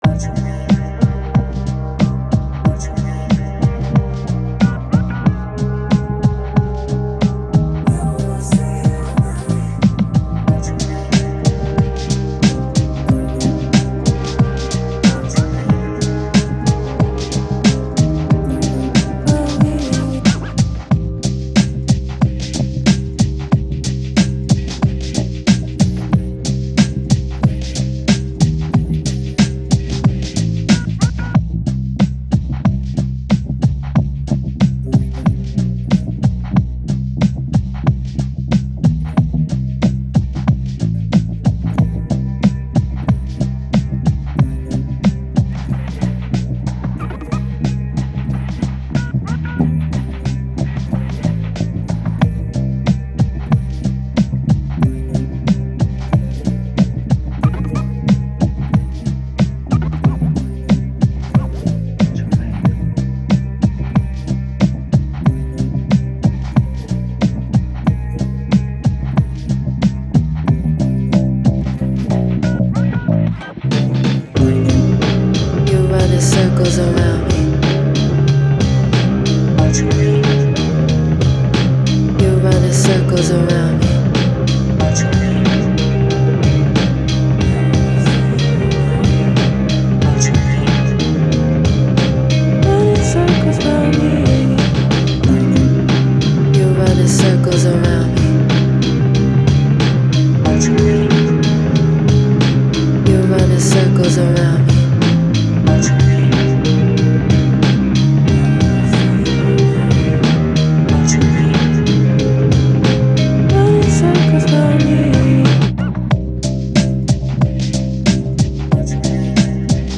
Genre Hip Hop